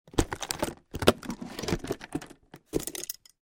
Врач берет стетоскоп и надевает на шею